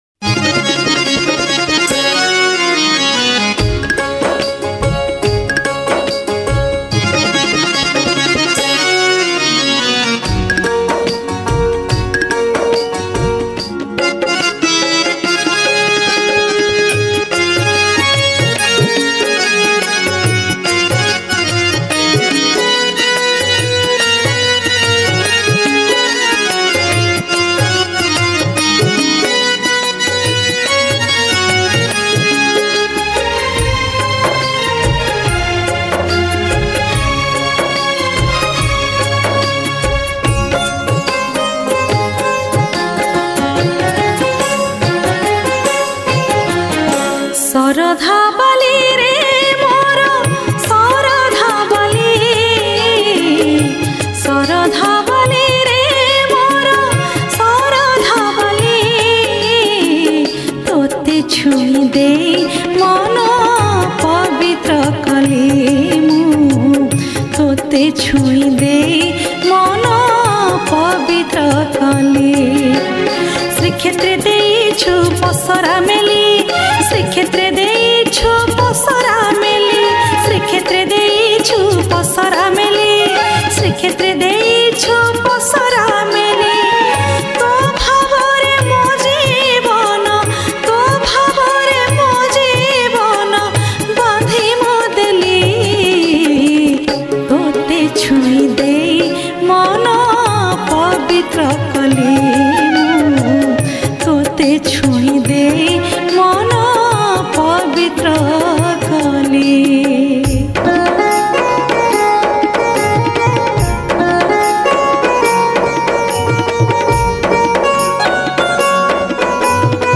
Rath Yatra Special Odia Bhajan